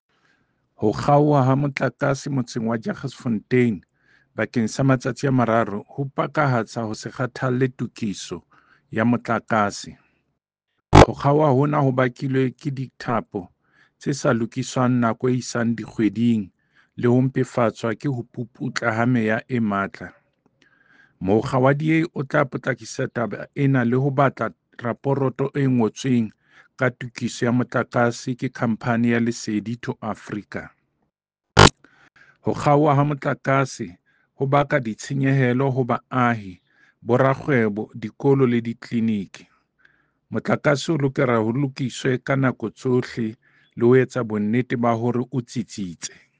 Sesotho soundbite by David Masoeu MPL.